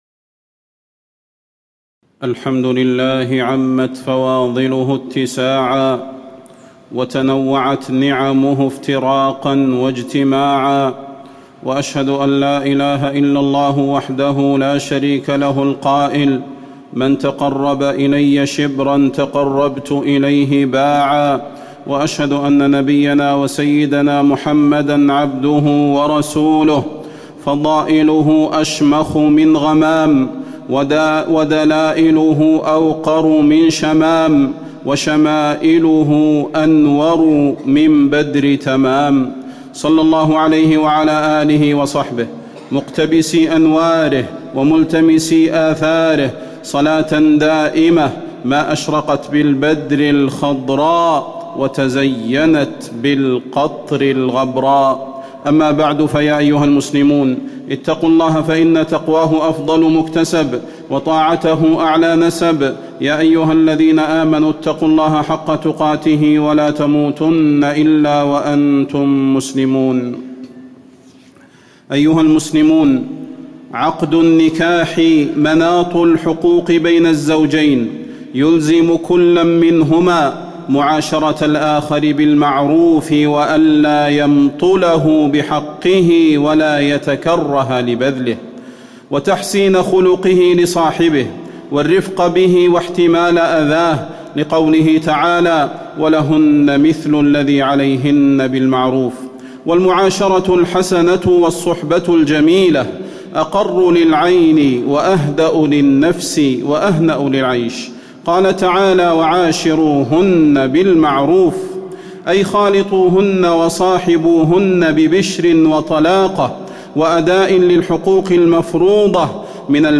فضيلة الشيخ د. صلاح بن محمد البدير
تاريخ النشر ١٣ جمادى الأولى ١٤٣٨ هـ المكان: المسجد النبوي الشيخ: فضيلة الشيخ د. صلاح بن محمد البدير فضيلة الشيخ د. صلاح بن محمد البدير الحياة الزوجية